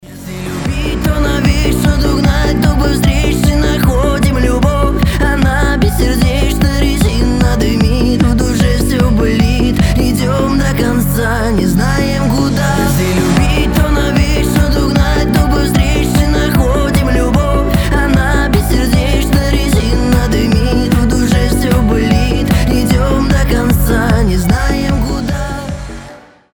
• Качество: 320, Stereo
грустные
быстрые